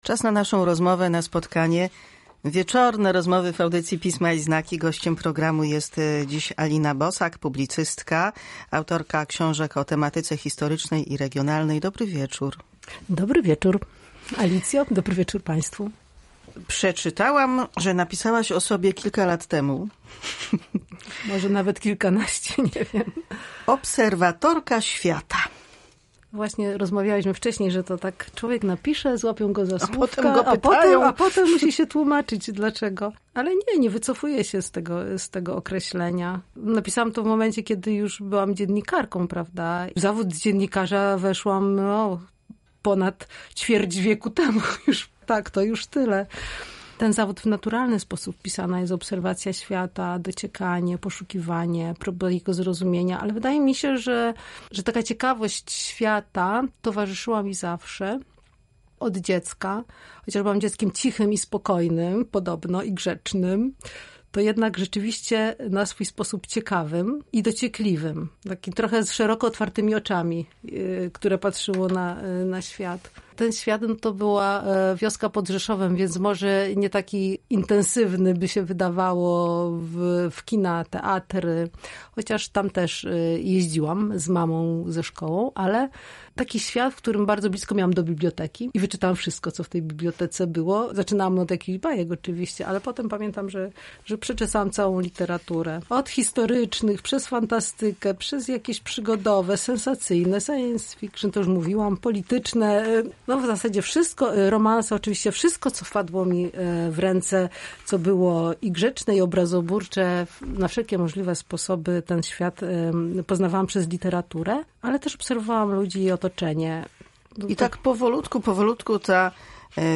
W audycji Pisma i znaki rozmowa o ocalaniu pamięci, wrażliwości na detale, szczegóły, które dla kogoś mogą być mało ważne.